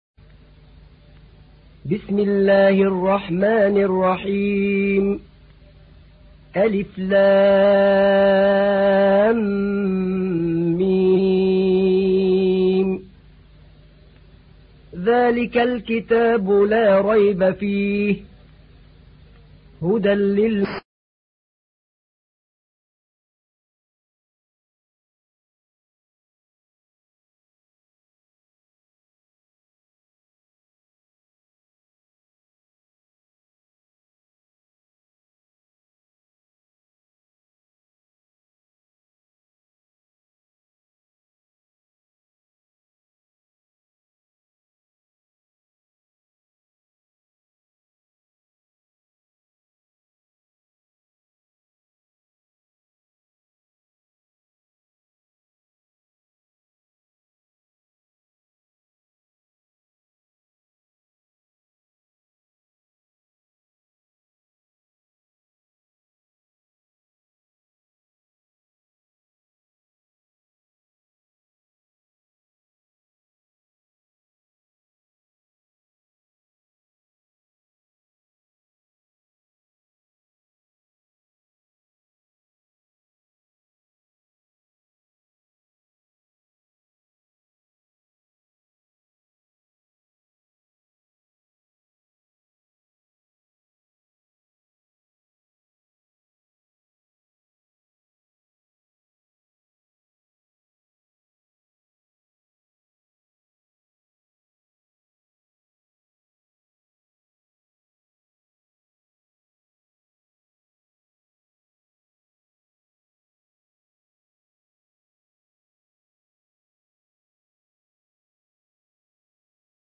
تحميل : 2. سورة البقرة / القارئ أحمد نعينع / القرآن الكريم / موقع يا حسين